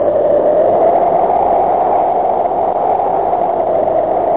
Amiga 8-bit Sampled Voice
1 channel
windnoise.mp3